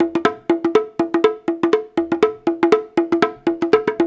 120 -UDU B08.wav